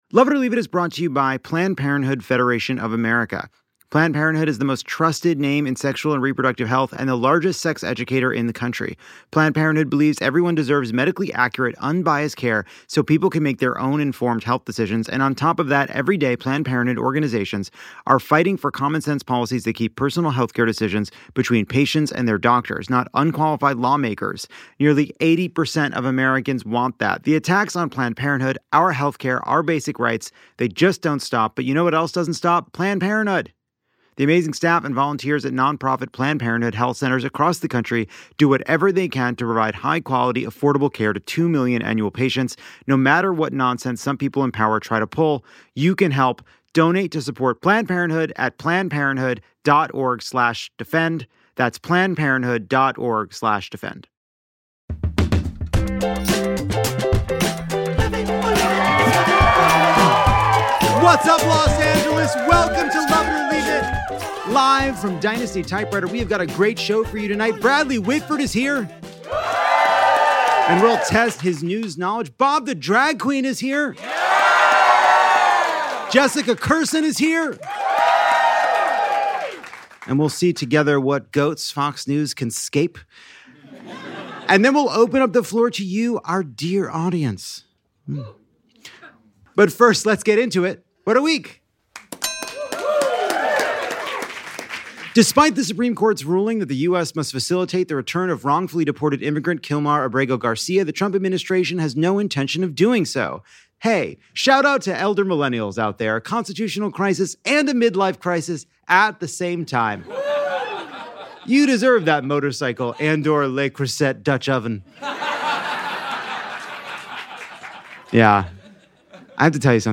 This week, we have Bradley Whitford on the end of Handmaid's Tale and the TV business, Bob the Drag Queen on Harriet Tubman's music career, and Jessica Kirson on life, love, and lesbian pants. Then we end on a few audience questions about executive orders, Republican drag names and more.